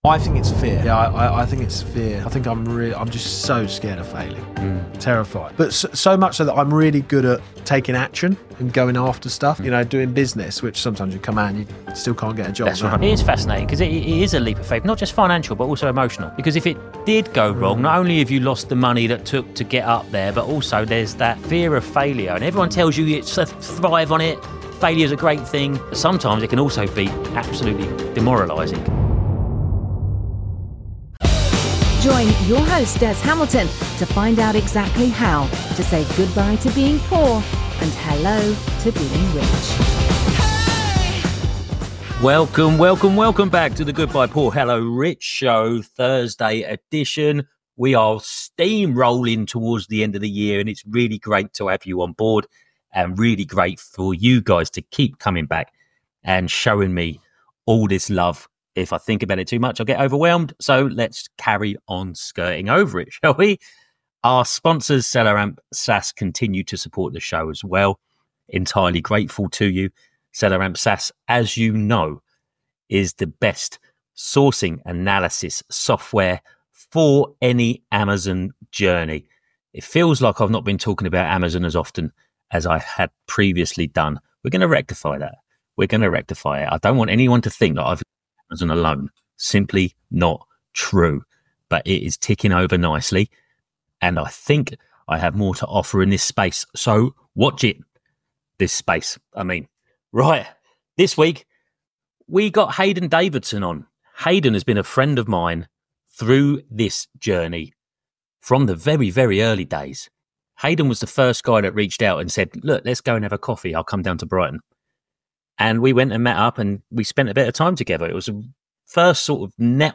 We sat down in a fantastic podcast studio to chat about: